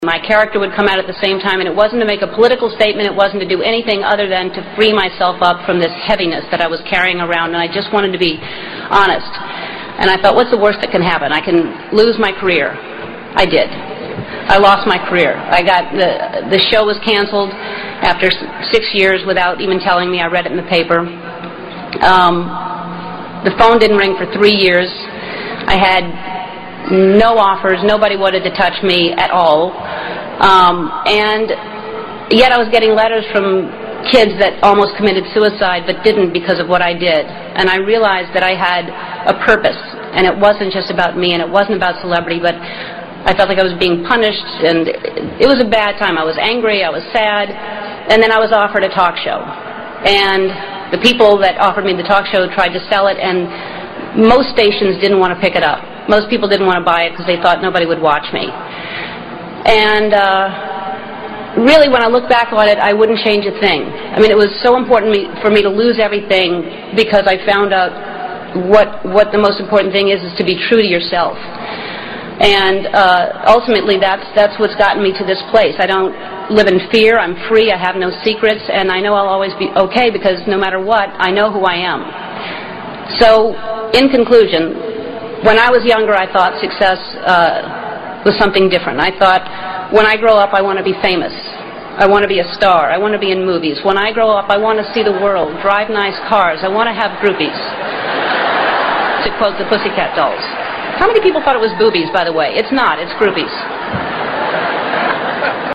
在线英语听力室名校励志英语演讲 70:做真正的自己的听力文件下载,名校励志演讲关于世界名人在全球名校演讲，内容附带音频和中英双语字幕。